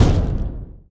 EXPLOSION_Short_Smooth_Crackle_stereo.wav